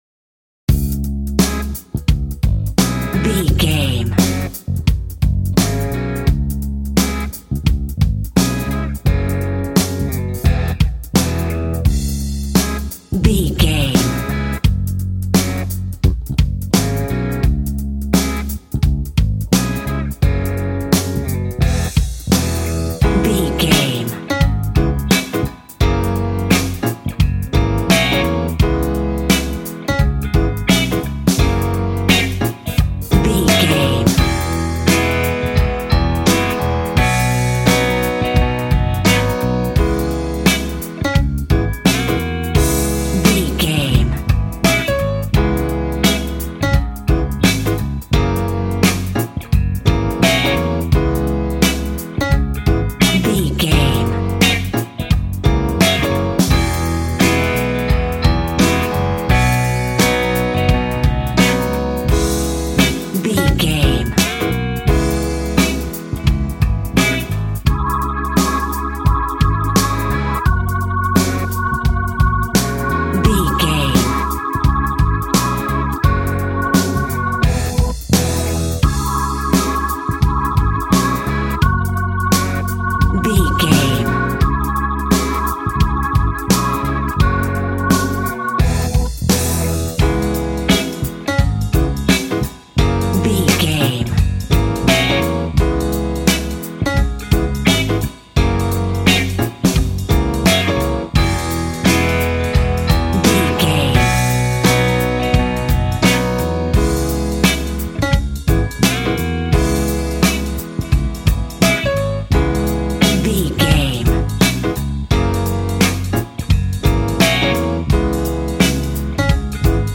Aeolian/Minor
D♭
sad
mournful
hard
bass guitar
electric guitar
electric organ
drums